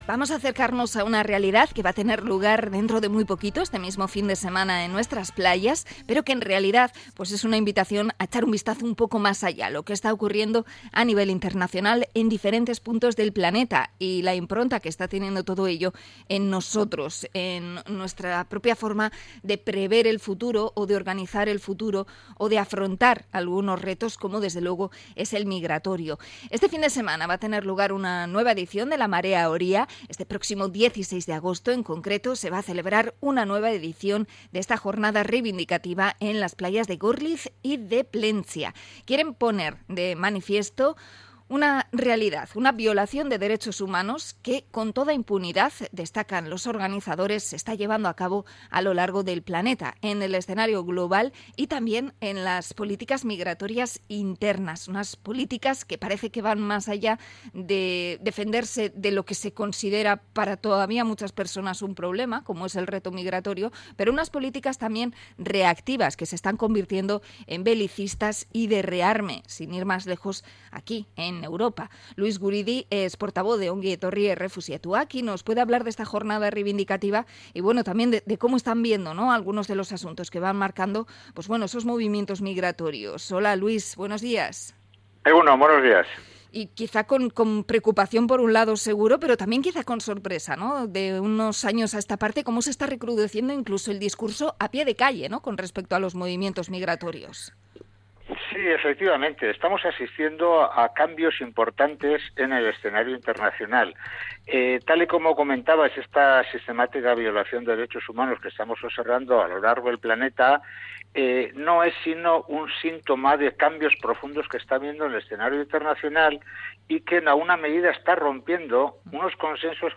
Entrevista a Ongi Etorri Errefuxiatuak